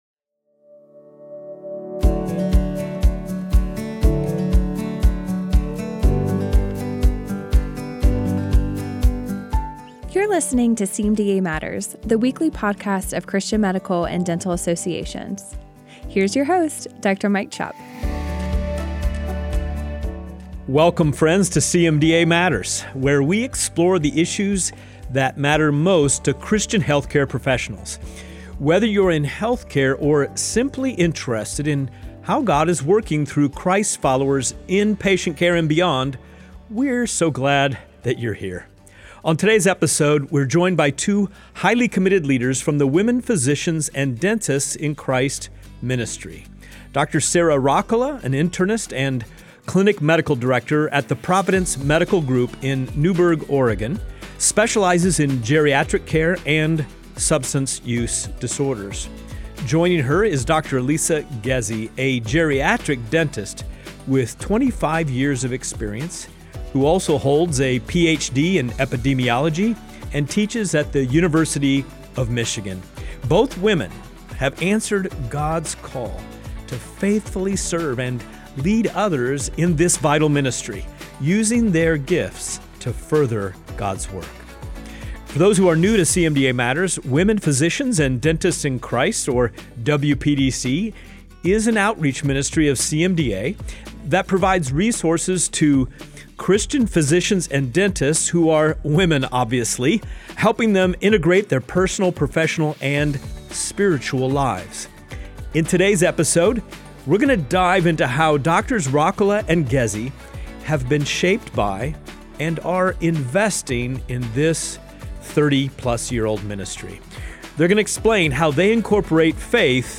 In this episode of CMDA Matters, we’re joined by two incredible leaders from our Women Physicians and Dentists in Christ (WPDC) ministry.